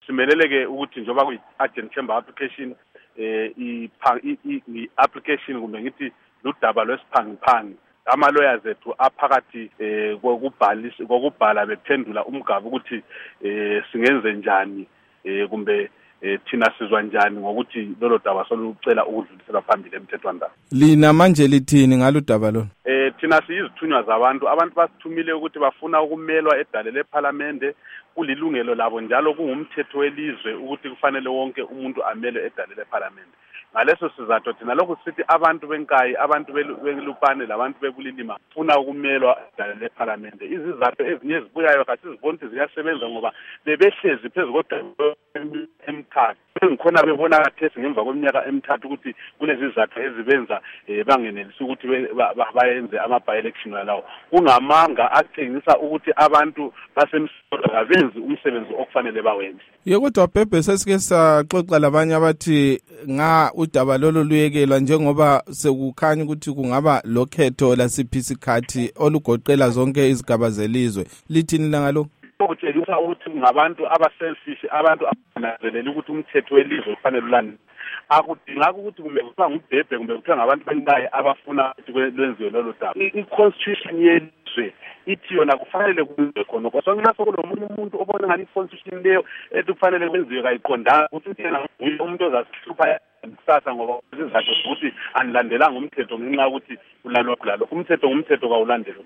Ingxoxo loMnu. Abednico Bhebhe